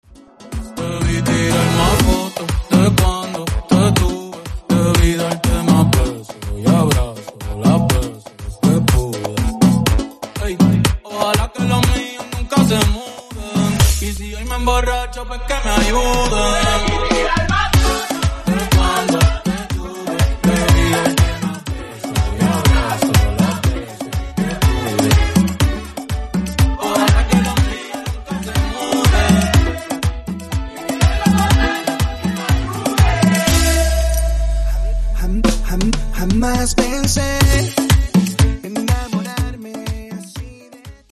Latin Pop , Mambo